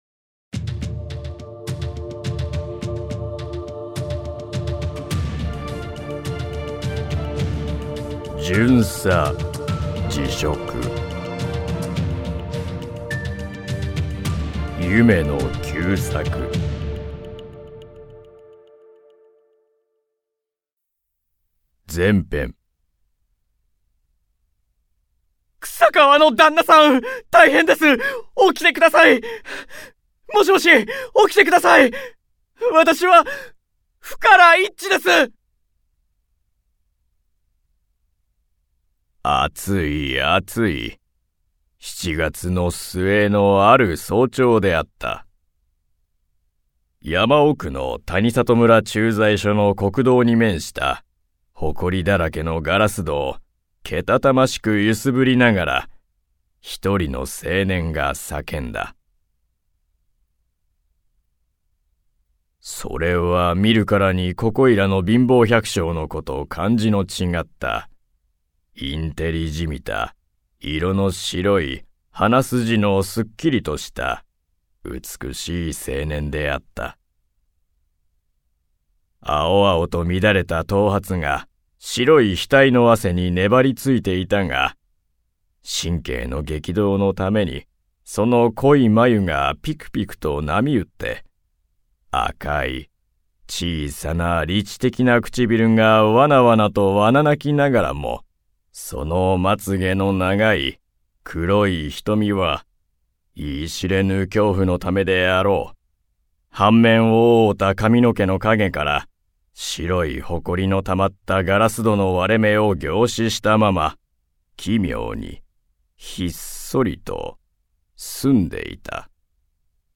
[オーディオブック] 夢野久作「巡査辞職」